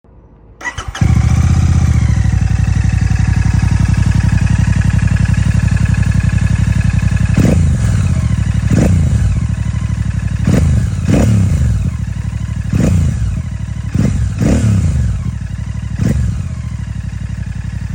Experience the roar! Click below to listen to our exhaust sound.
• Signature Sound: The C-V4 delivers a rich, classic thump — deeper and more refined than stock — true to the legendary Peashooter tone.
Expect a deep, resonant, classic thump — faithful to the vintage Peashooter style, but tuned with modern richness and clarity.
ExhaustSound_V4.mp3